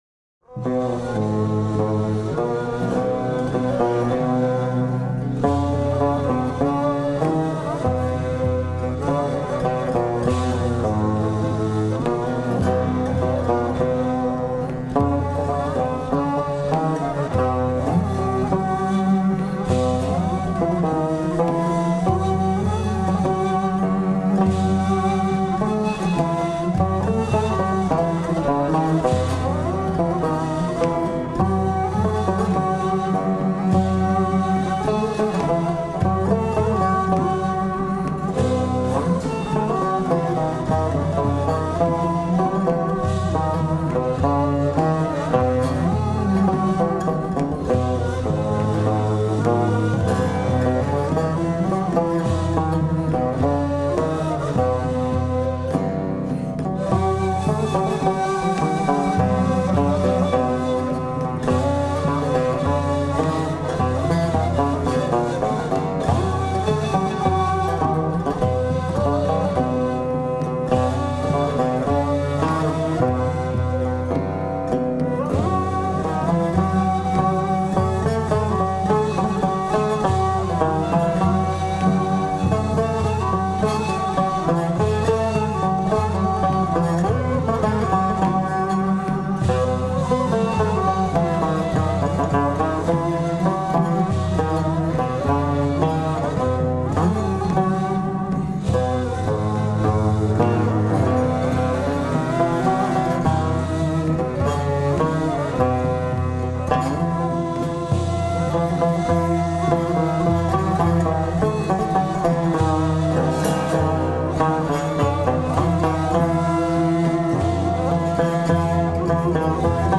Genre: Turkish & Ottoman Classical.